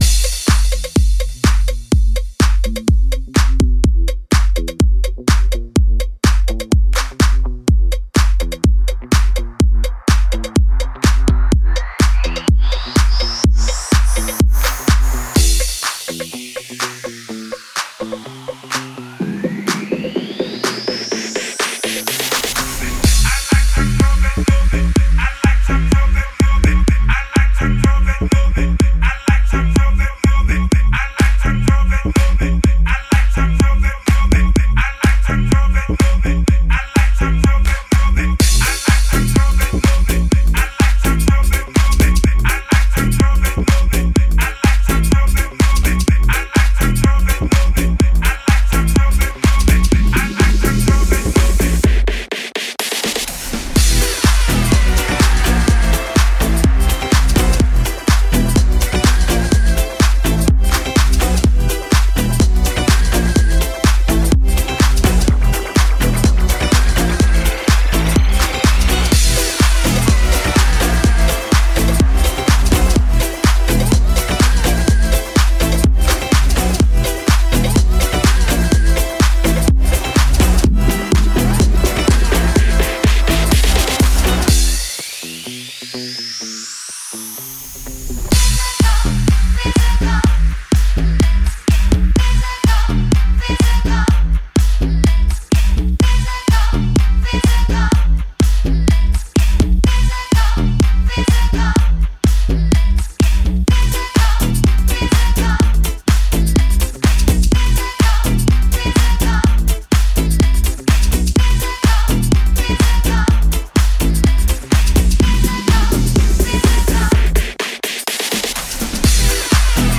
Genres: Hip Hop, Rock, Top 40